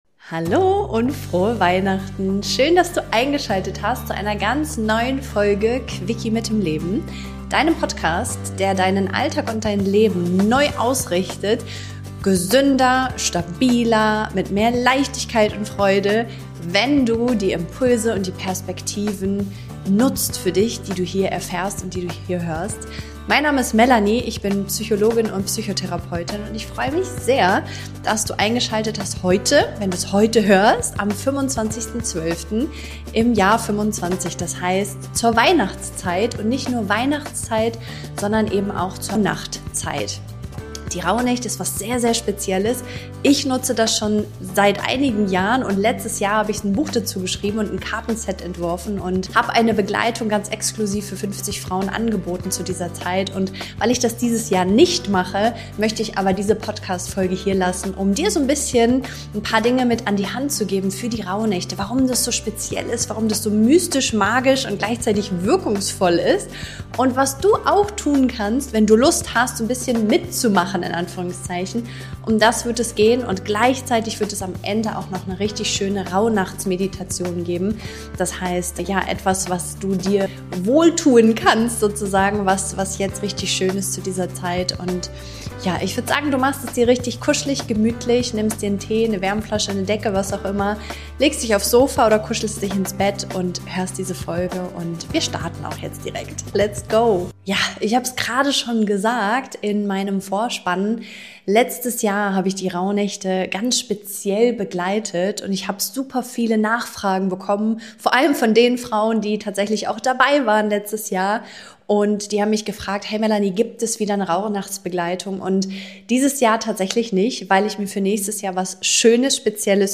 In dieser besonderen Folge von Quickie mit dem Leben teile ich mit dir, was es mit dieser Schwellenzeit auf sich hat, wie du sie auf deine Weise gestalten kannst und warum dein Nervensystem gerade jetzt bereit ist für echte Neuausrichtung. Dich erwartet Wissen, Tiefe und eine geführte Meditation, die dich mit dir selbst verbindet – jenseits von Druck, To-dos und Perfektion.